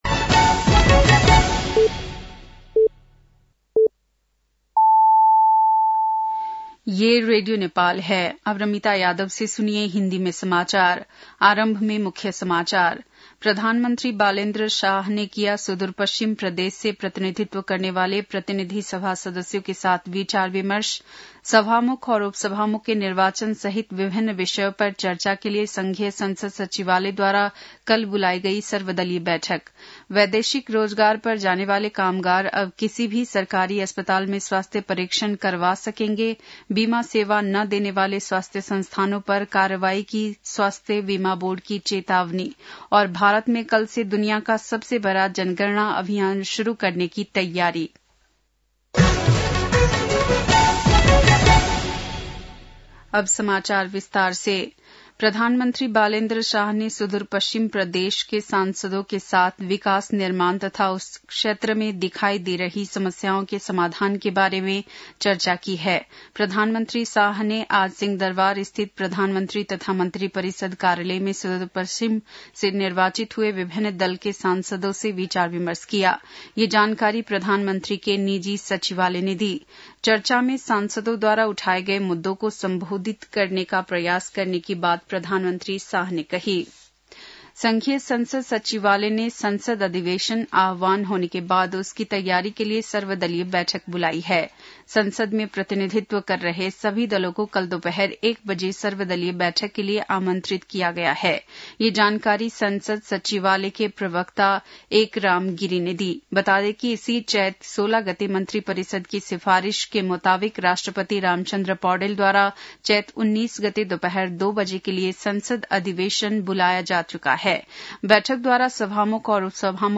बेलुकी १० बजेको हिन्दी समाचार : १७ चैत , २०८२